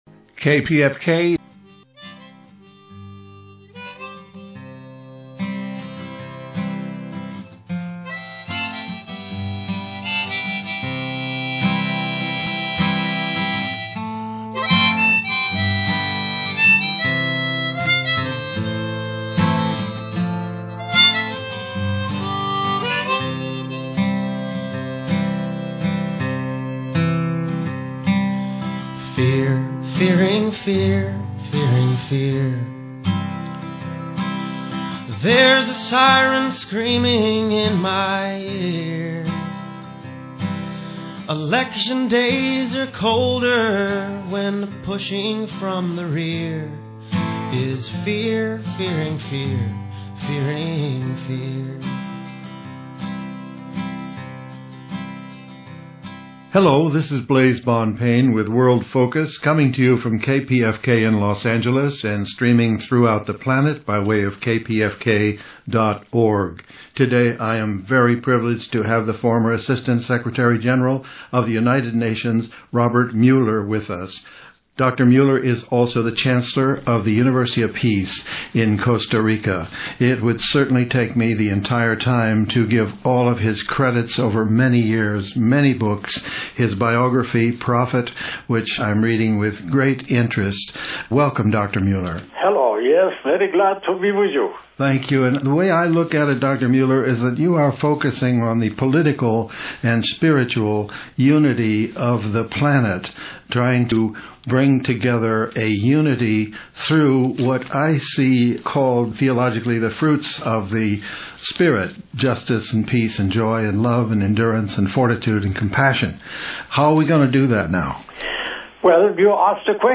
Robert Muller Radio Interview